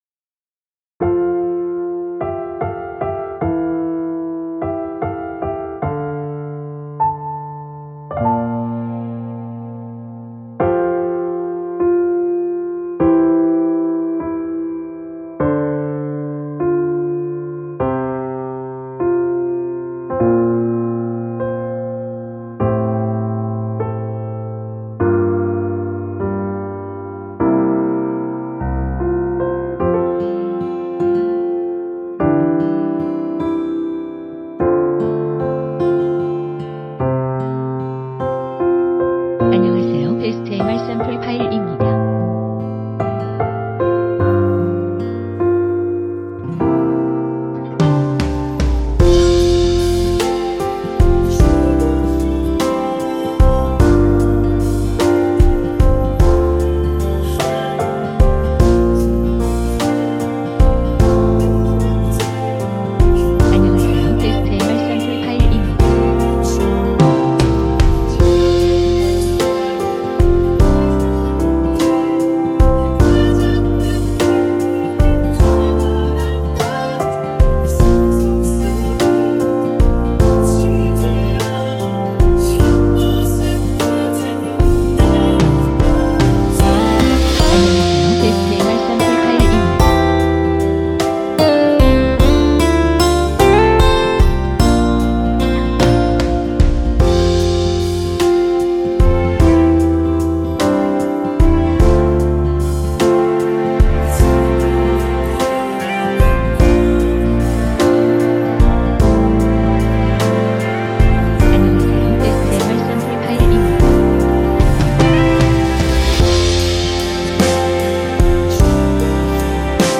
(멜로디,코러스 MR)에서 전체 미리듣기 가능하십니다.
원키 코러스 포함된 MR입니다.(미리듣기 확인)